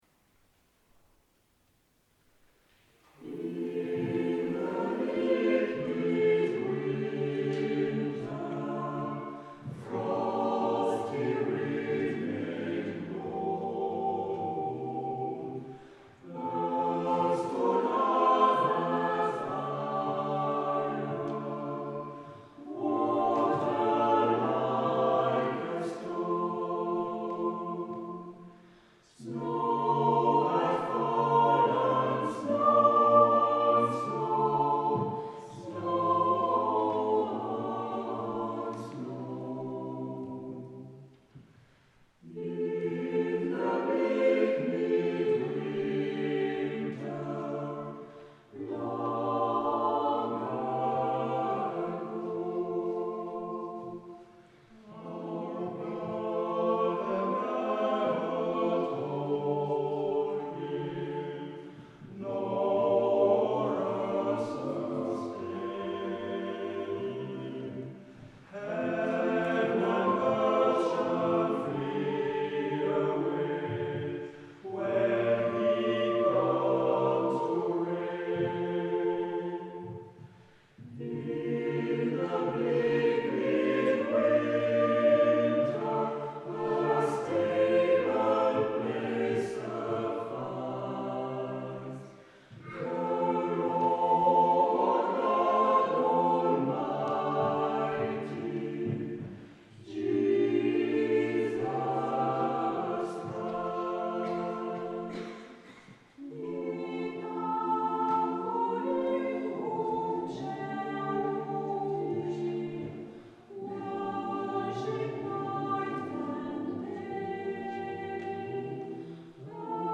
- Le dimanche 5 décembre 2010 à 17h00 à l'église de St-George, VD, Suisse.
Un petit extrait de St-George, live et enregistré avec les moyens du bord :